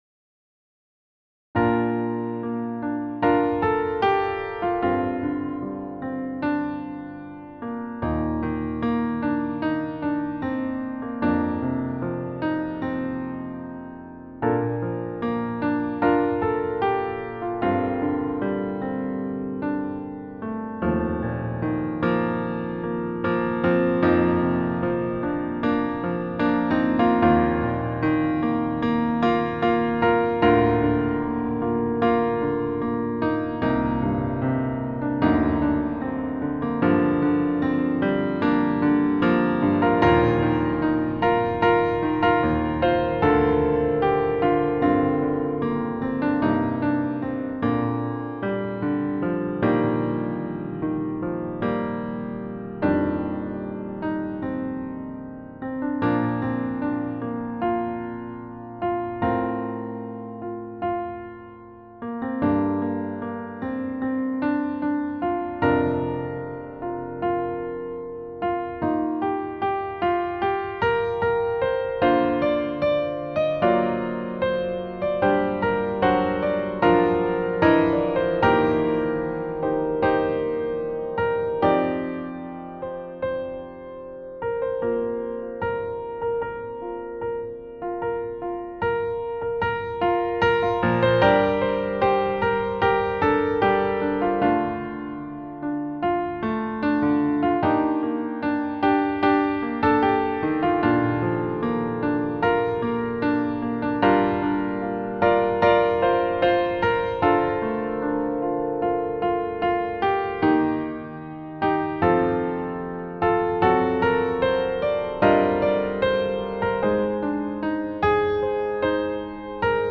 未分類 バラード ピアノ 切ない 夕焼け 悲しい 懐かしい 穏やか 音楽日記 よかったらシェアしてね！